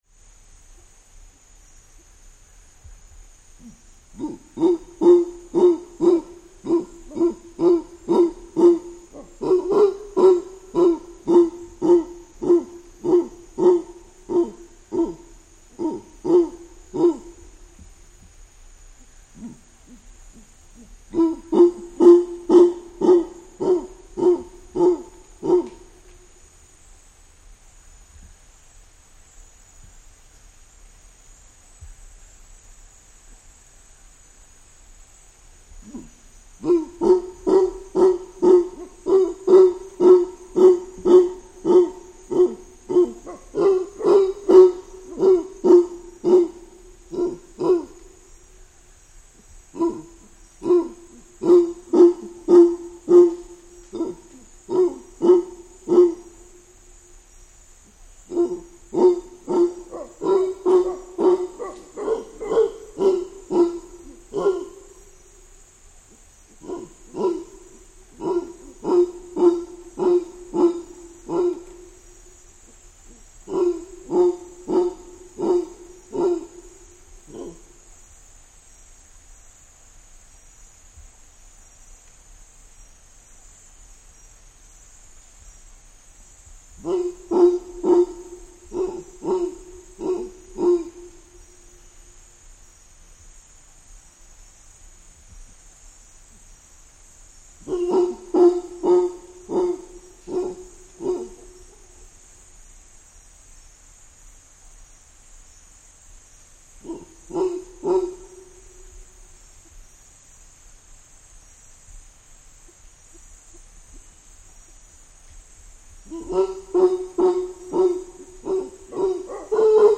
На этой странице собраны разнообразные звуки ревунов — от громких рыков до отдаленных эхо в джунглях.
Взрослый колумбийский ревун